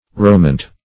romant - definition of romant - synonyms, pronunciation, spelling from Free Dictionary Search Result for " romant" : The Collaborative International Dictionary of English v.0.48: Romant \Ro*mant"\, n. A romaunt.